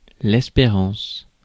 Ääntäminen
US : IPA : [hɔʊp]